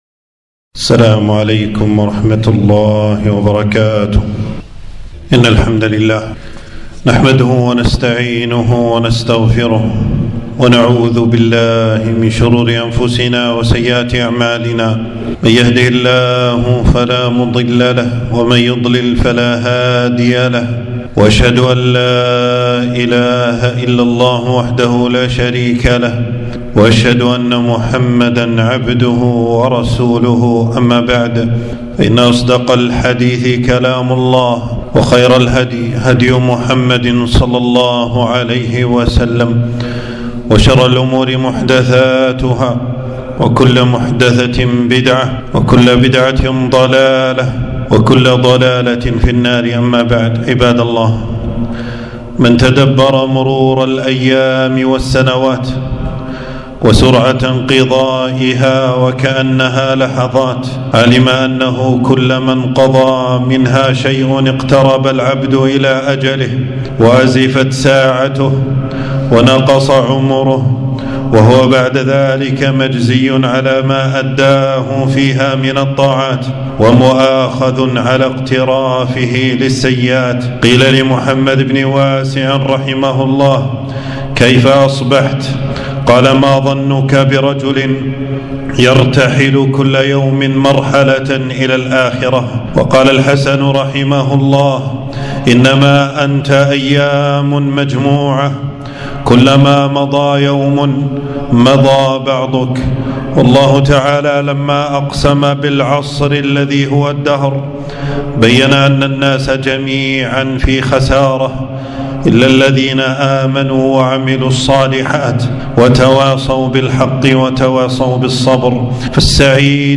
خطبة - استغلال الإجازة في الطاعات